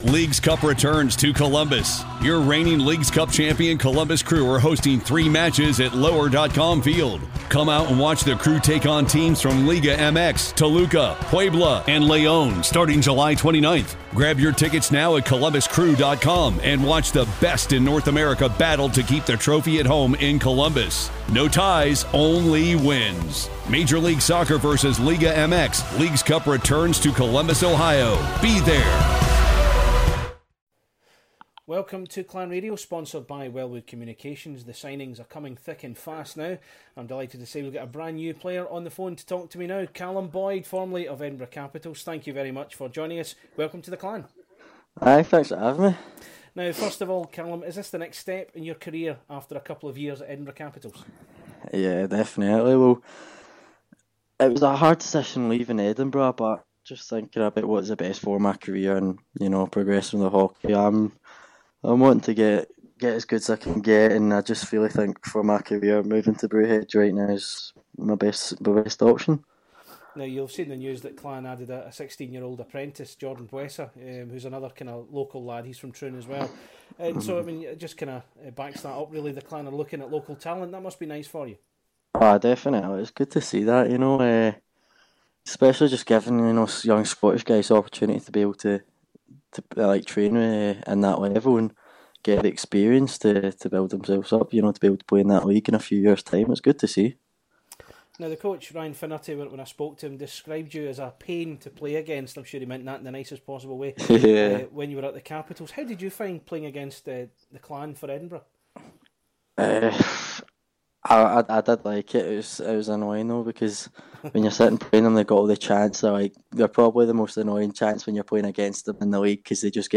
Clan Chat / INTERVIEW